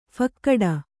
♪ phakkaḍa